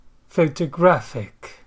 sound_loud_speaker photographic /ˌfəʊtəˈgræfɪk/